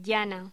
Locución: Llana
voz
Sonidos: Hostelería